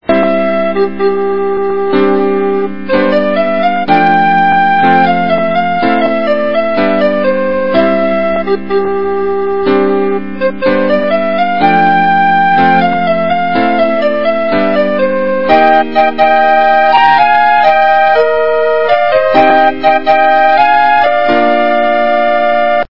качество понижено и присутствуют гудки